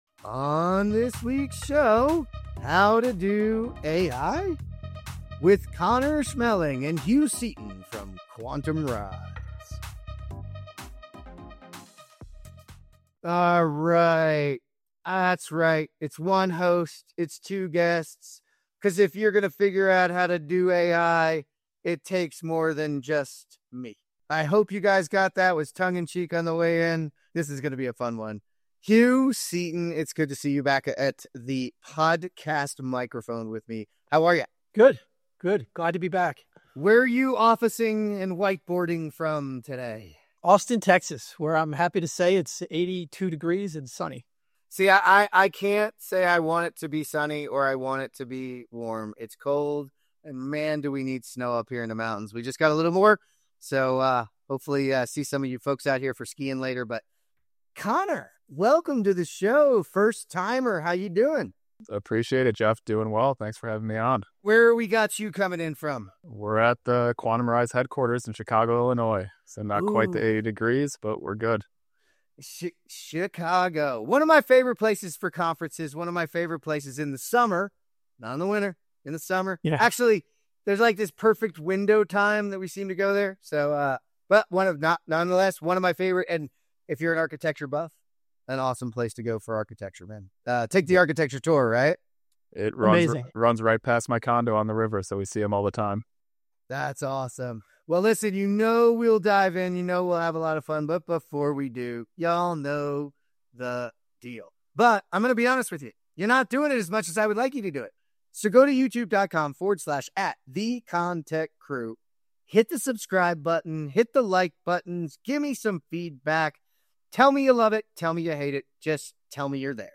While AI is generating excitement across industries, construction companies often struggle with where to start, what to automate, and how to ensure meaningful ROI. This conversation breaks down the path from curiosity to implementation, showing how AI can shift from buzzword to business advantage, if done right.